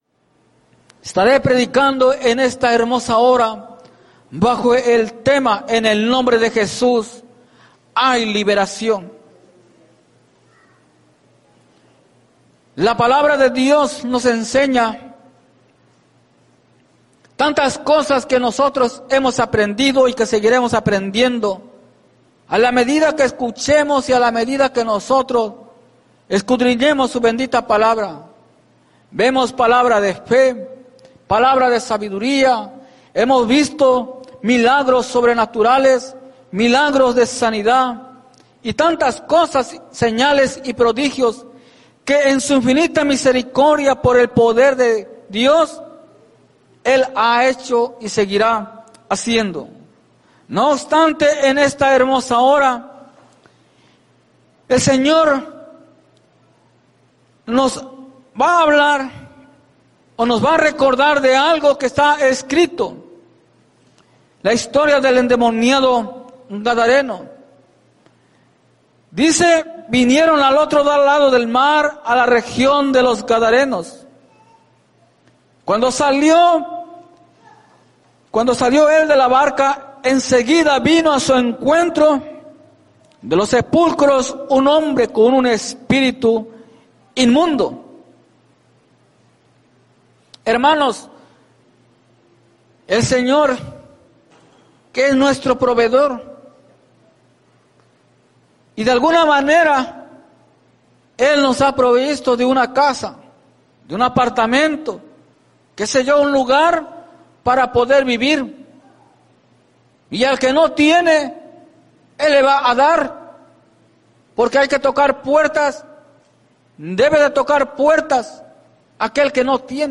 En el nombre de Jesús hay liberación Predica